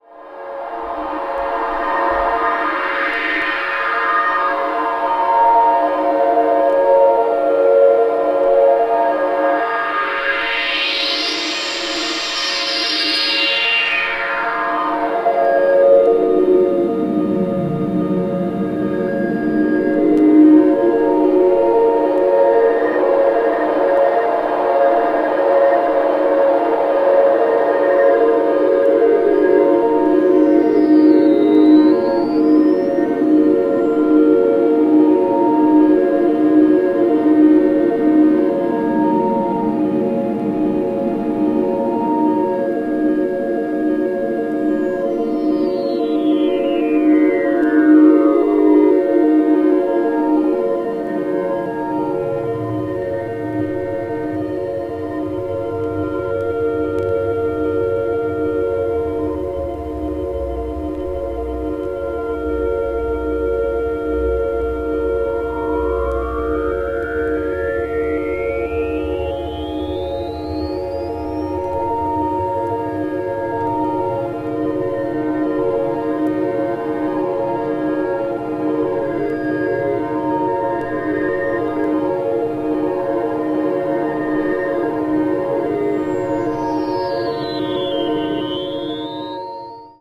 ambient   electronic   experimental   new age   synthesizer